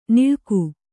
♪ niḷku